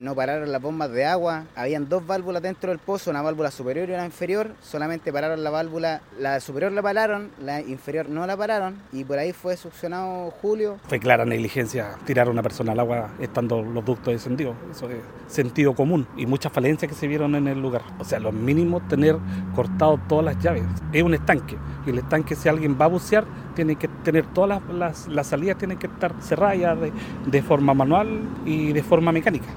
En conversación con Radio Bío Bío